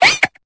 Cri de Psystigri dans Pokémon Épée et Bouclier.